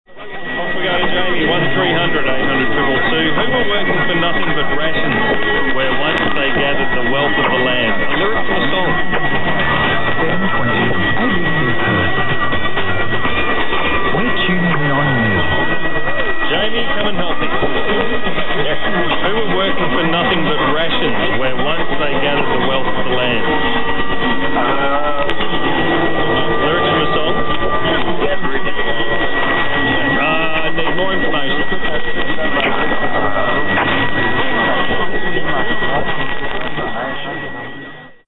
ABC Perth, Perth, WA on 720 kHz (local station identification '720 ABC Perth') and ABC Kimberley, Kununurra, WA on 819 kHz (regional station identification 'ABC Local Radio WA').